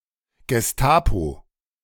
The Geheime Staatspolizei ([ɡəˈhaɪmə ˈʃtaːtspoliˌtsaɪ] , lit.''Secret State Police''), abbreviated Gestapo ([ɡəˈstaːpo]
De-Gestapo2.ogg.mp3